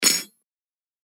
戦闘 （163件）
刀の鍔3.mp3